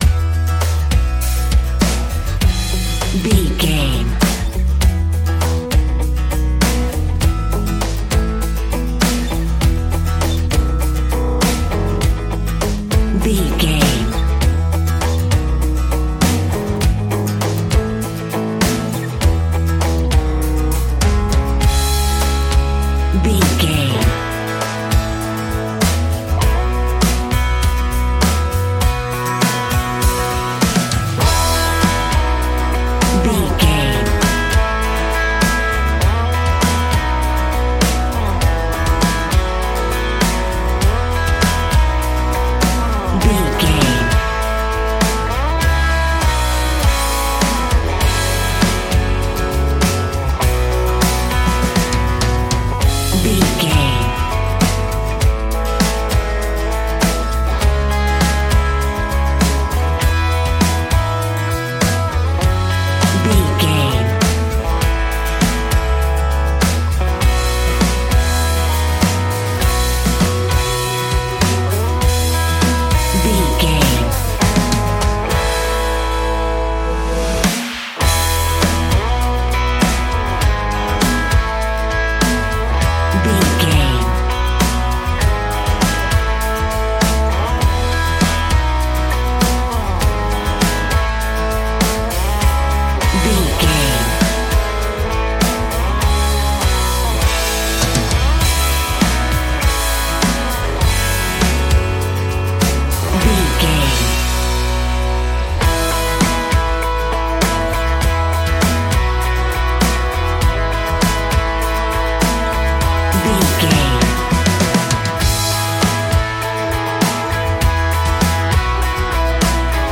Aeolian/Minor
electric guitar
acoustic guitar
bass guitar
drums
country rock